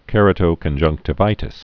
(kĕrə-tō-kən-jŭngktə-vītĭs)